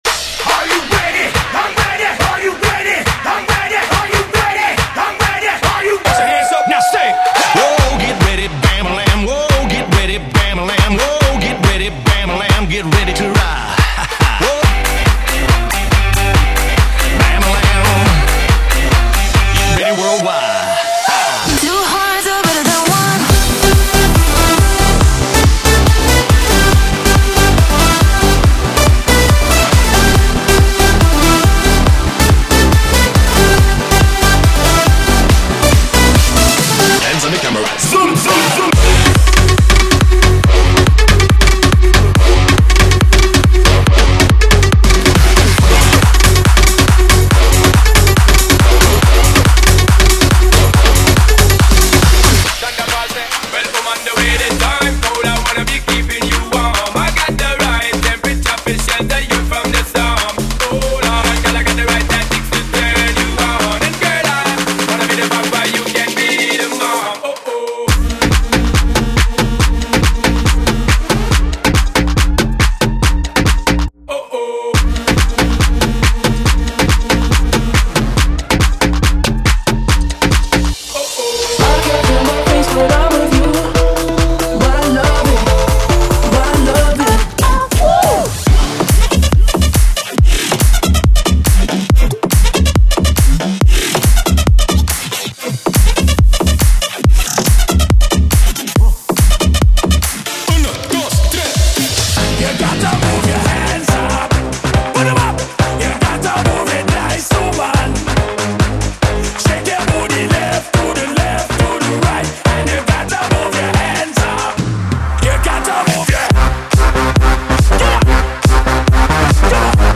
BPM: 140|150 (60:00)
Format: 32COUNT